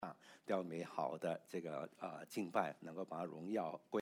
October 19, 2025 乐意奉献的心 Passage: 2 Corinthians 8:1-10 Service Type: 主日证道 Download Files Notes « 禿子和母熊的故事 作贵重的器皿 » Submit a Comment Cancel reply Your email address will not be published.